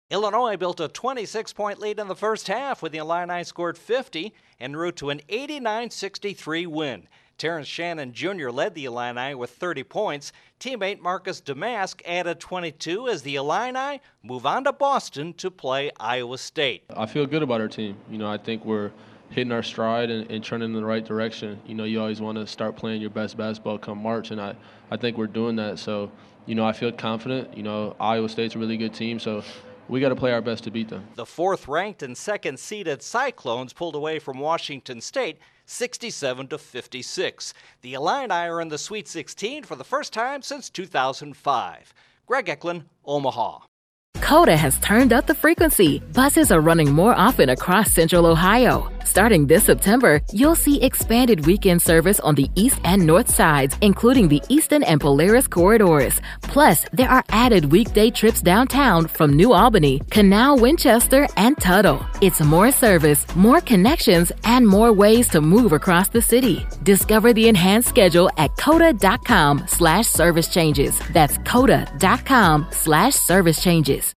The Sweet 16 will include Illini and Cyclones. Correspondent